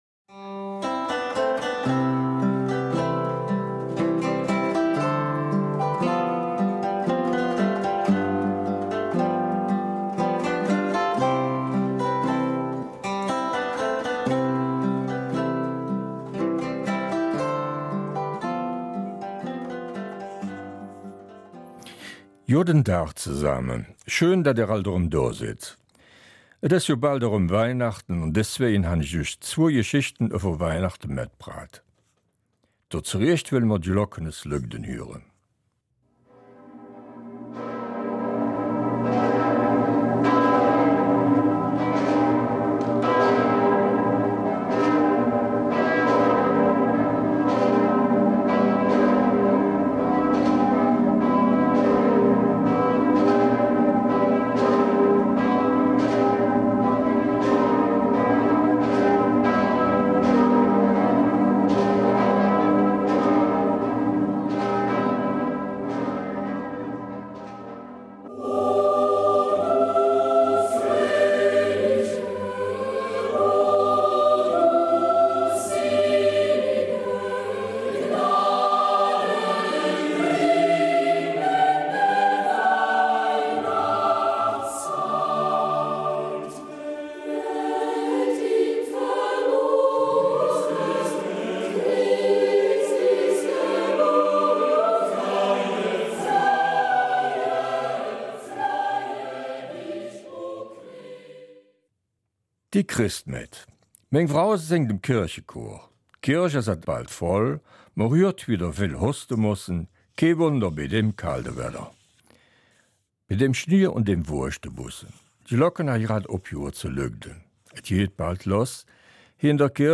Eingebettet sind die Geschichten dem Anlass gemäß in traditionelle Weihnachtslieder.
Eifeler Mundart: Weihnachtsgeschichten 21.